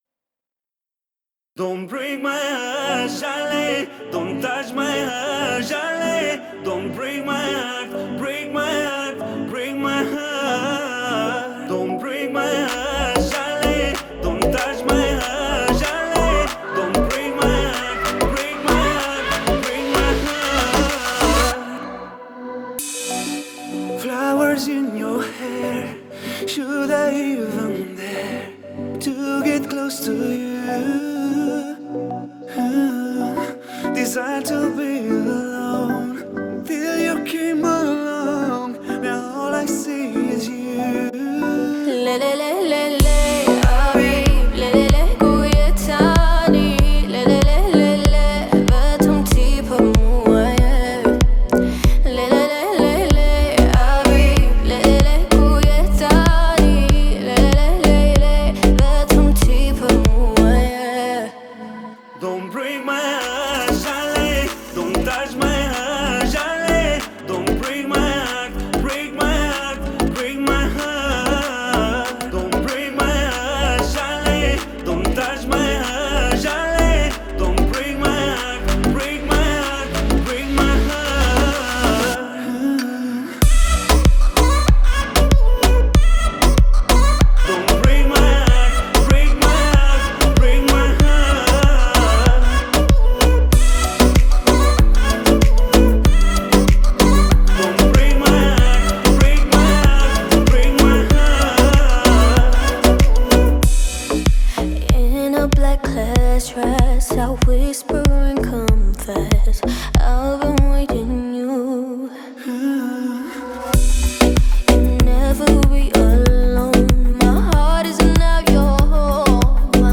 мелодичными вокалами
динамичными битами